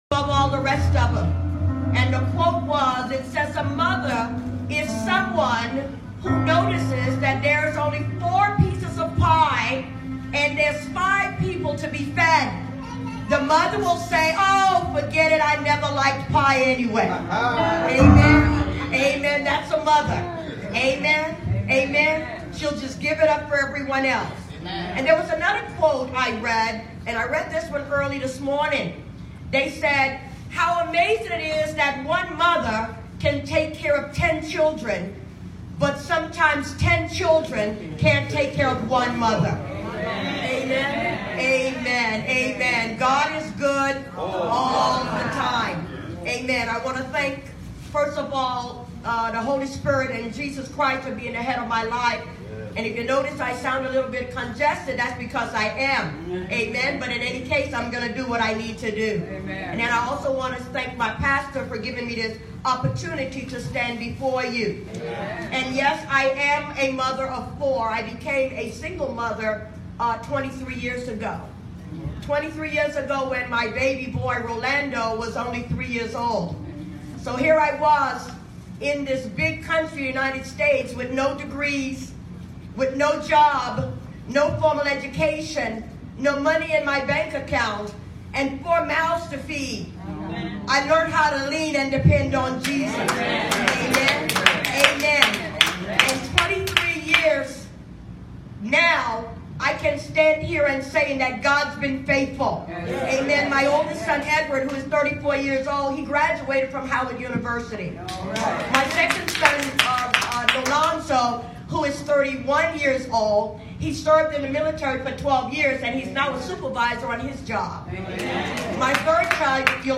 SUNDAY 11:00am New Jerusalem MB Church